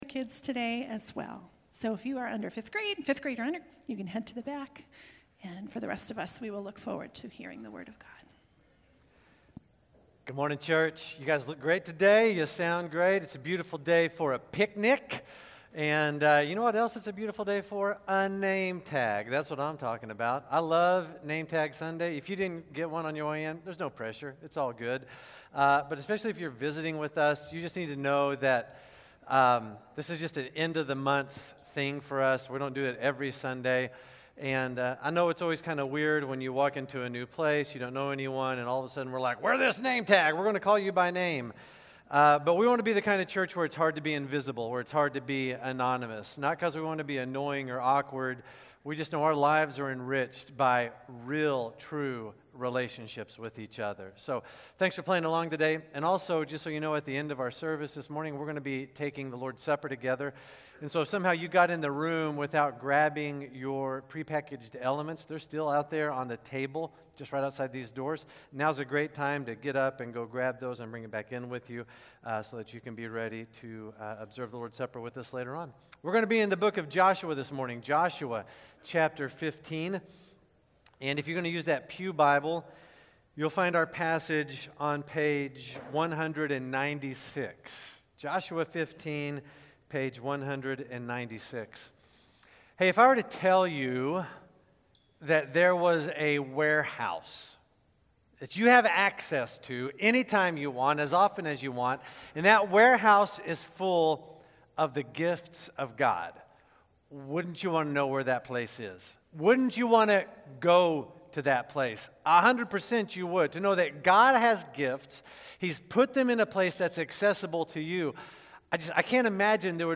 South Shore Baptist Church Sermons Podcast - Every Good Gift - Joshua 15 | Free Listening on Podbean App